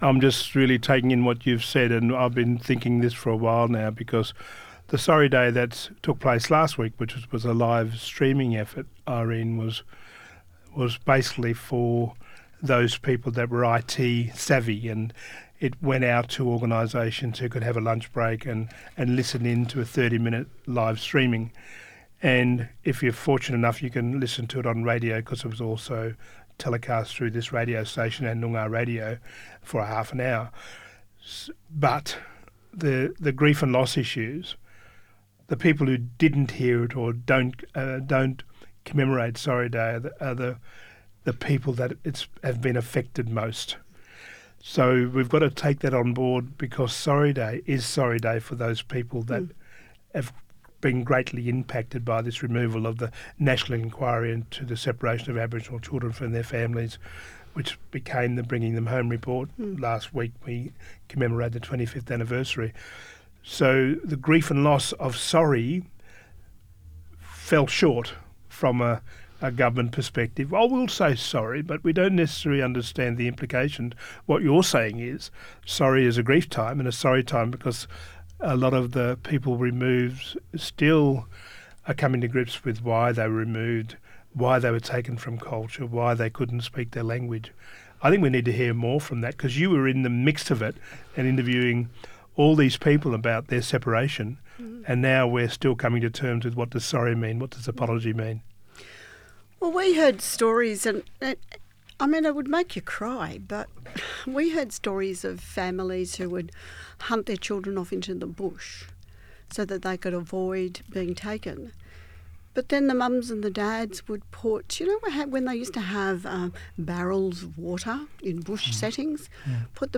This is an enduring conversation surrounding the historical development of Aboriginal Affairs and Hope for the future wellbeing of Indigenous Australian communities.